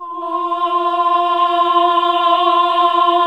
AAH F2 -L.wav